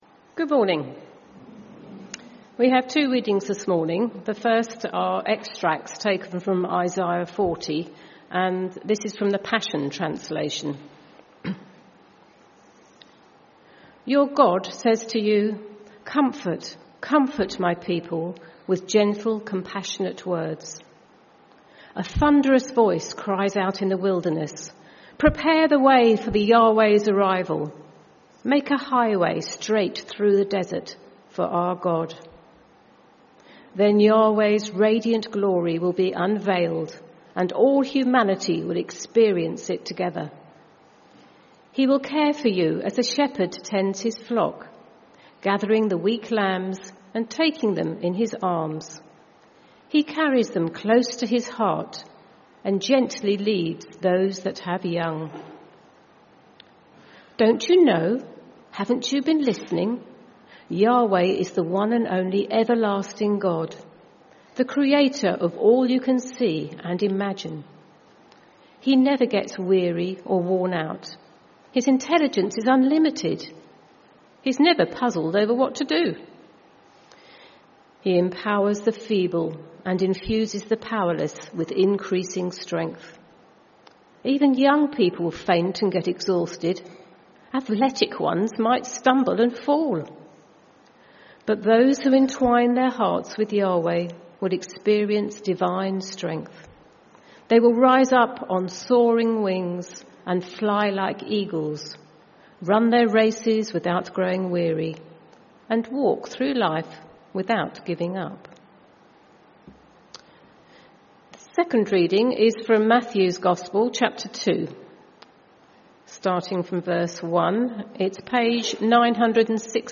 Sunday Sermon 4th January 2026